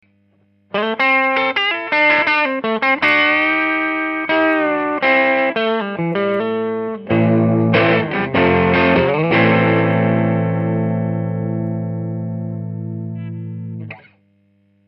meme gratte type télé, meme ampli B4 (EF184+6V6 bias 70%) sans correction de tonalité, meme position du potard de volume de l'ampli, meme micro, a la meme position/distance... il n'y a pas de normalisation des samples, juste une conversion en MP3 codec LAME qui compresse un peu j'en suis désolé.
sample 4 - transfo ESO SE 5W 5k-8r avec inversion du sens du primaire, primaire 4H
sample 3 vs 4 : la phase du son a tres peu d'influence sur le son d'une guitare. en wav le sample 4 me parrait légèrement plus "plein", avec un peu plus de corps mais ce n'est pas du tout évident.